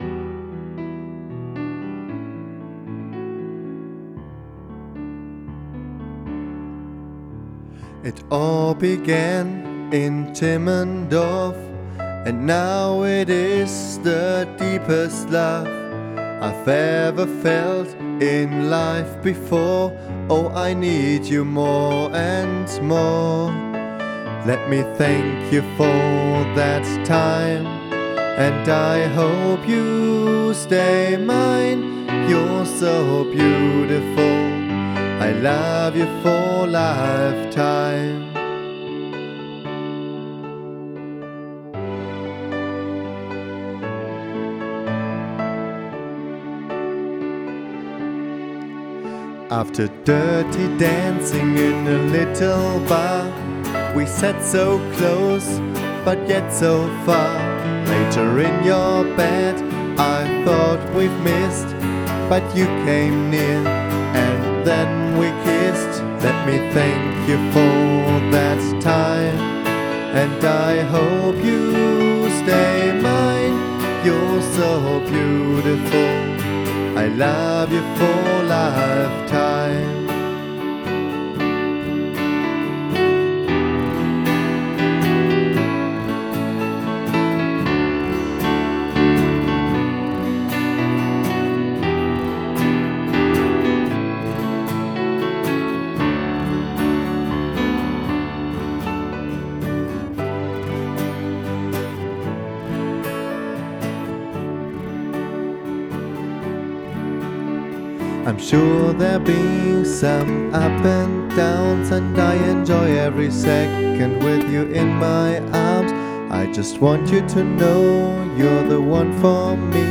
mit Gitarre